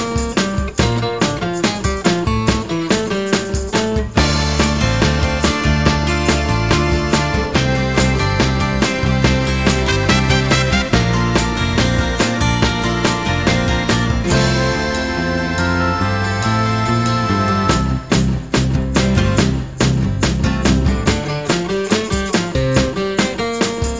With Harmony Pop (1960s) 1:56 Buy £1.50